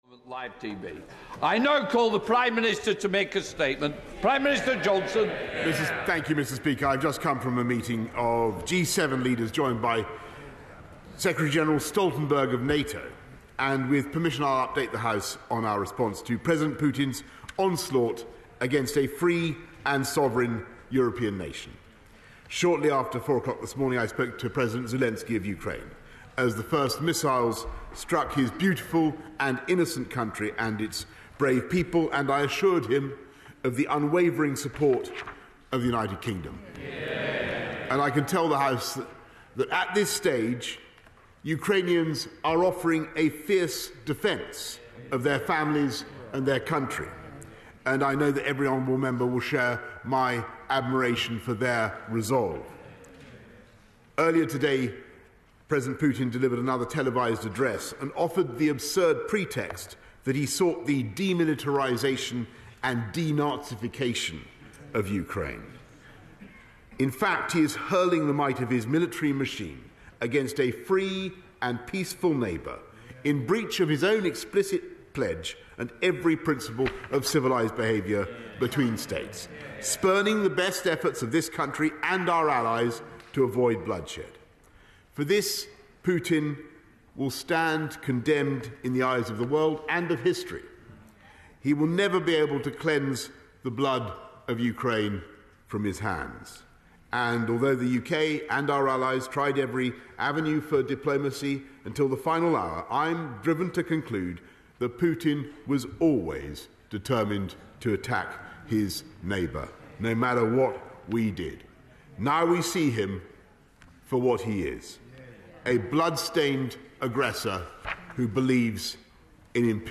borisjohnsonhocukrainerussia.mp3